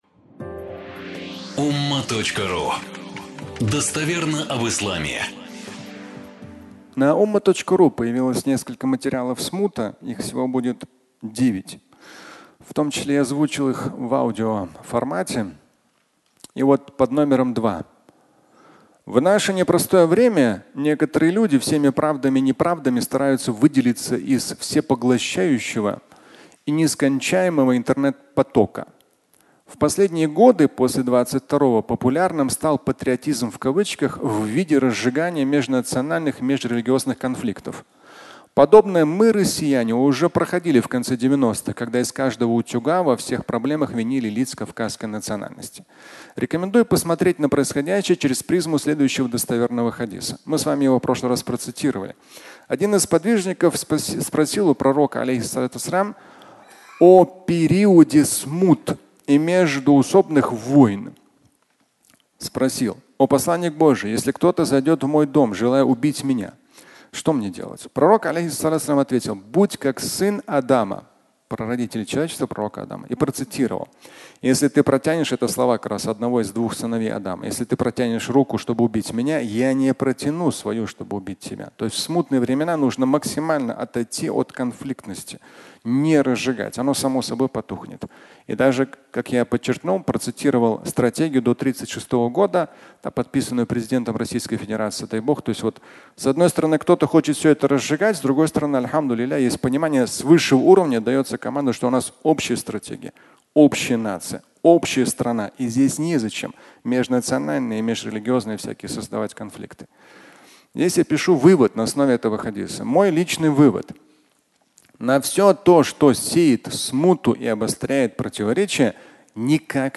Смута 2 (аудиолекция)
Фрагмент пятничной лекции